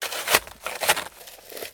dig1.ogg